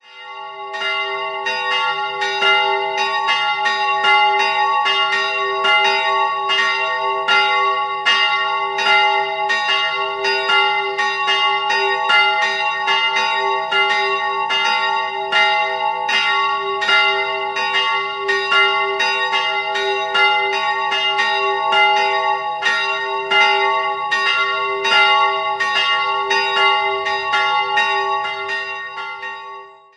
Die seelsorgliche Betreuung obliegt der Pfarrei Jachenhausen. 2-stimmiges Geläute: f''-b'' Die größere Glocke wurde 1861 von Spannagl in Regensburg gegossen, die kleinere 1990 bei Perner in Passau.